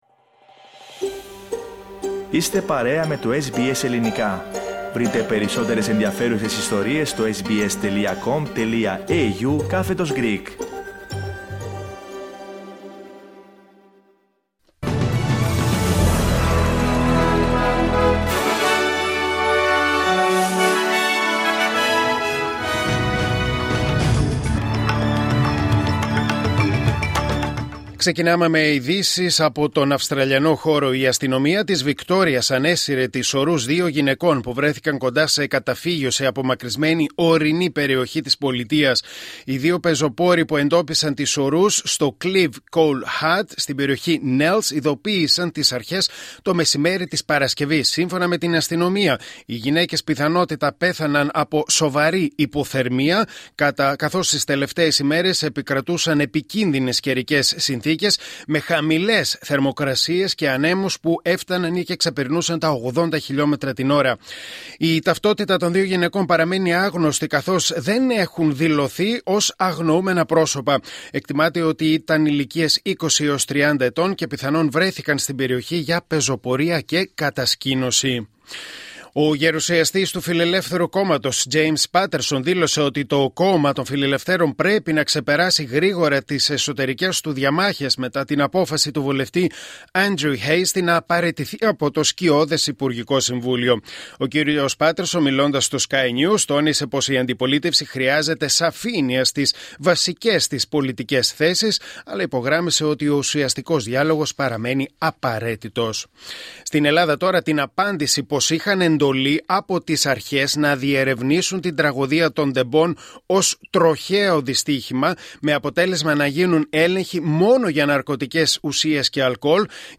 Δελτίο Ειδήσεων Κυριακή 05 Οκτωβρίου 2025